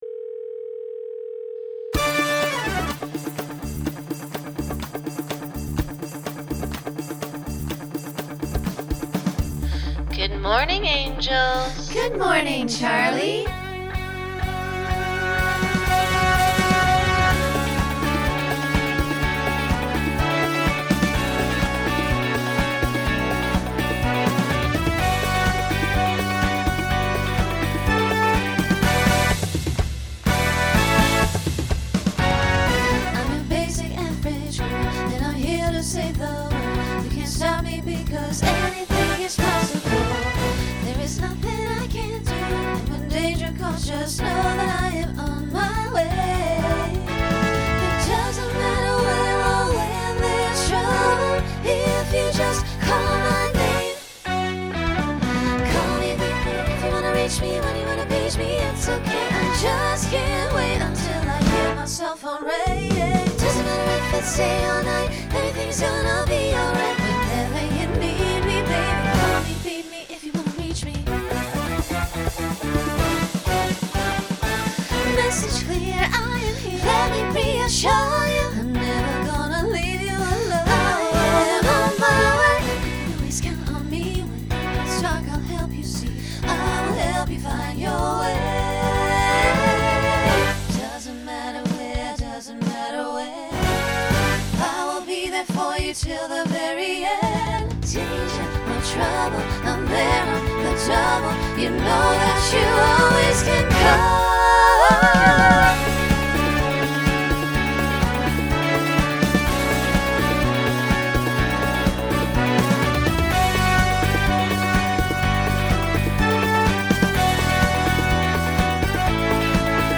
Genre Pop/Dance Instrumental combo
Voicing SSA